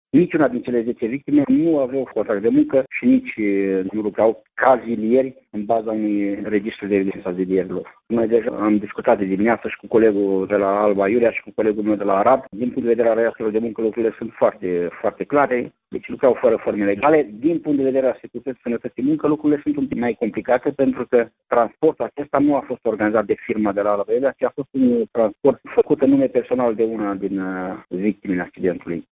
Cei 9 bărbaţi care au murit şi tânarul care a supravieţuit, după ce microbuzul în care se aflau a căzut în râul Bistriţa, lucrau „la negru” în Arad, pentru o firmă din Cugir, judeţul Alba, care executa lucrări de împăduriri – a declarat, pentru Radio România Iaşi şeful Inspectoratului Teritorial de Munca Neamţ, Ioan Popescu: